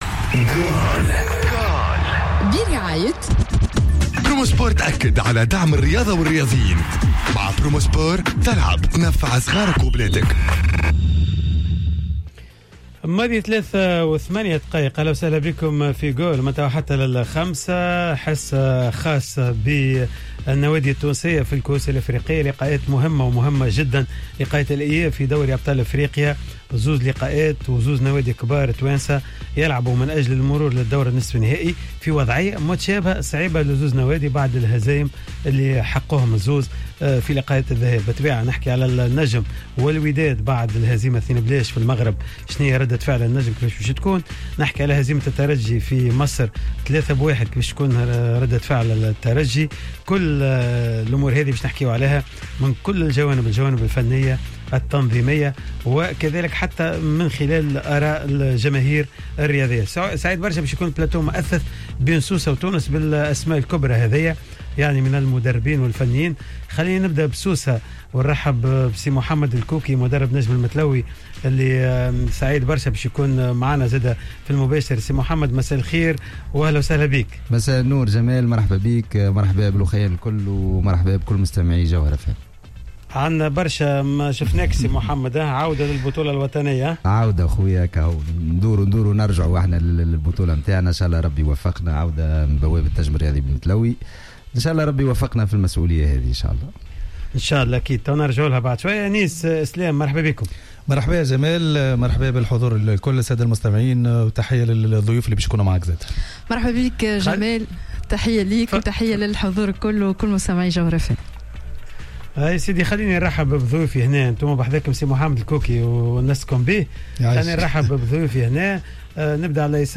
و كان في الحضور في استوديو جوهرة أف أم بالعاصمة